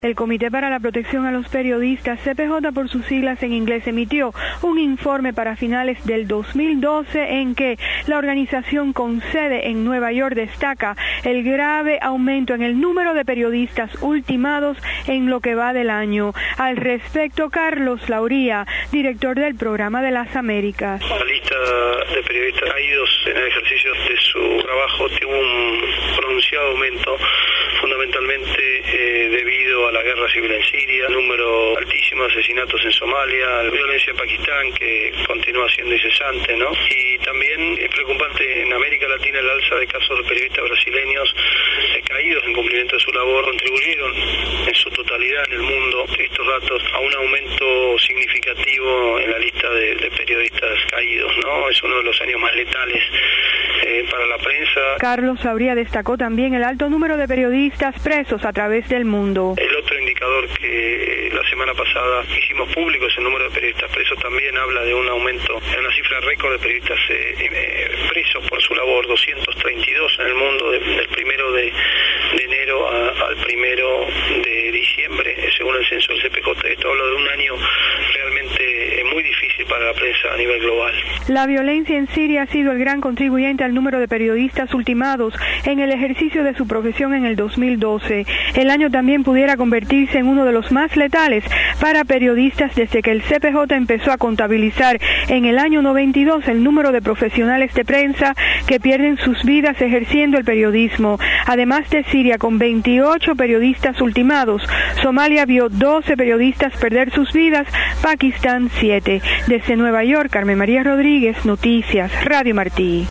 Desde Nueva York, reporta